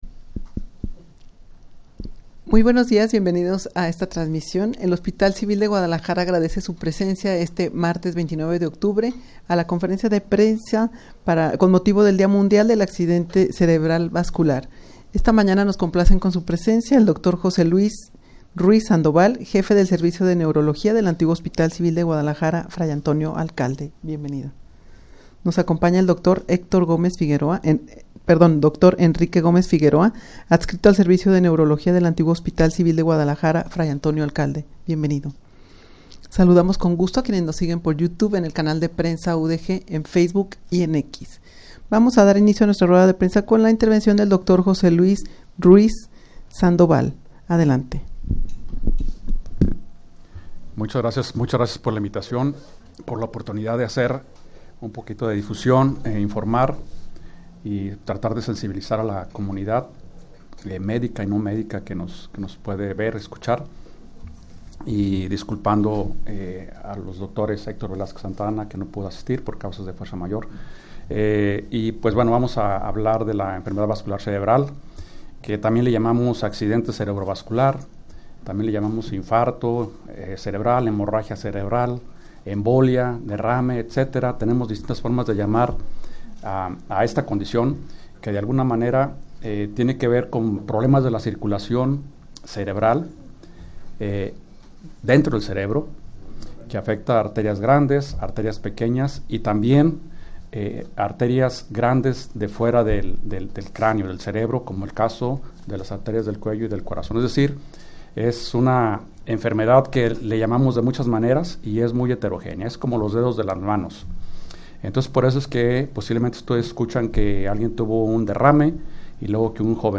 Audio de la Rueda de Prensa
rueda-de-prensa-con-motivo-del-dia-mundial-del-accidente-cerebral-vascular.mp3